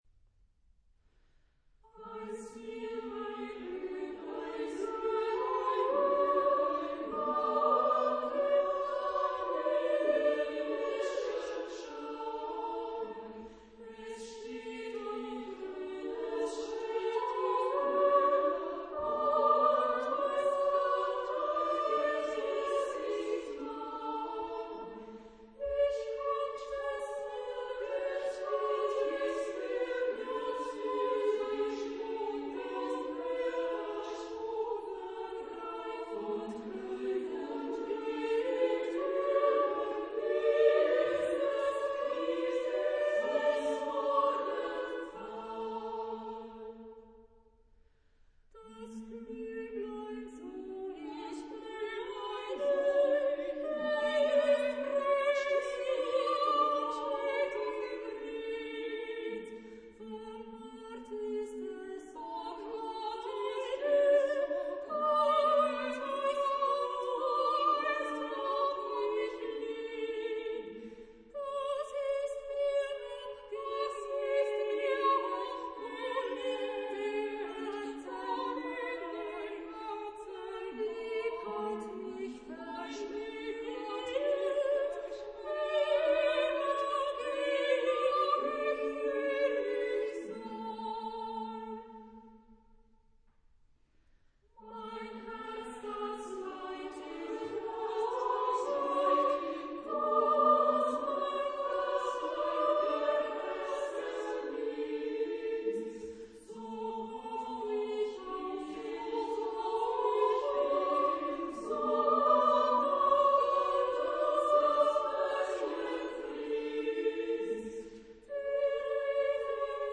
Genre-Style-Forme : Profane ; Populaire
Type de choeur : SSA  (3 voix égales de femmes )
Tonalité : sol majeur
Réf. discographique : Internationaler Kammerchor Wettbewerb Marktoberdorf